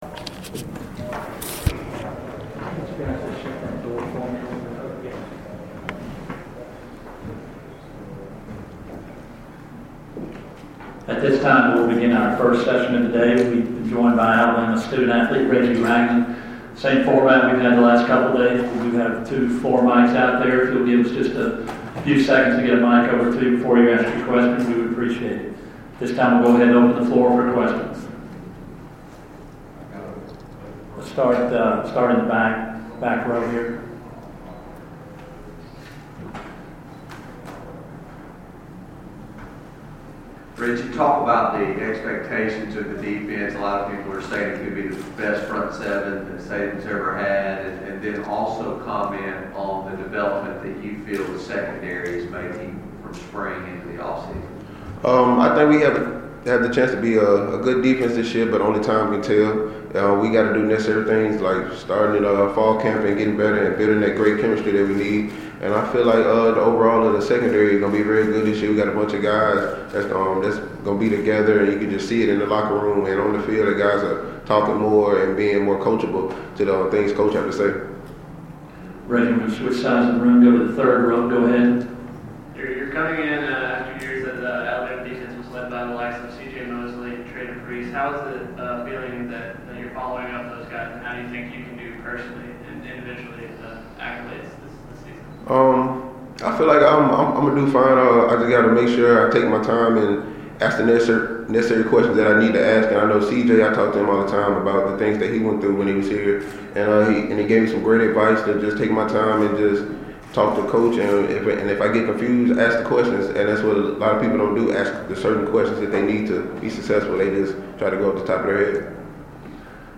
Alabama linebacker at SEC Media Days 2015